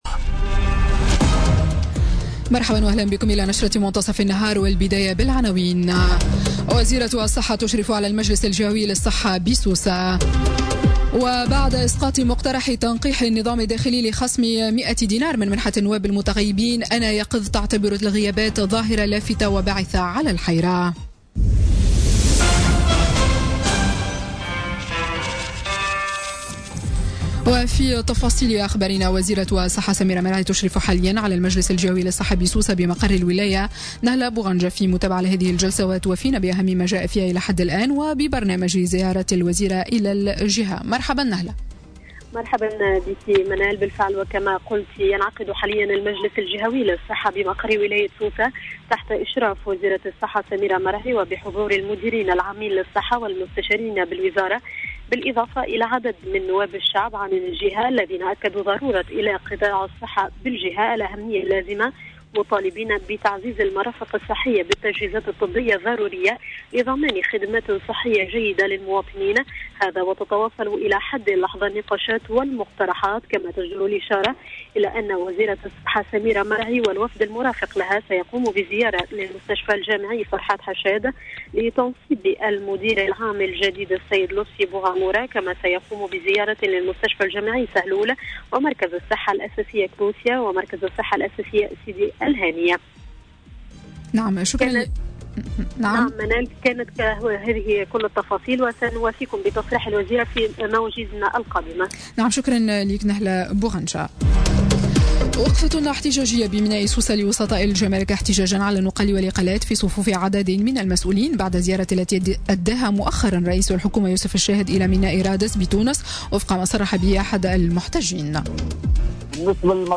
نشرة أخبار منتصف النهار ليوم السبت 17 جوان 2017